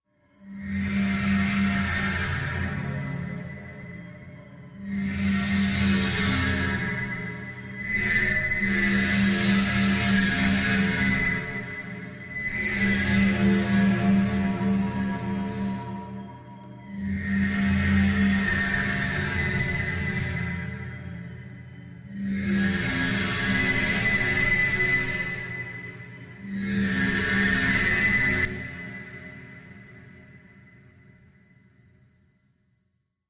Over and Under Metallic Voice Breathy Screech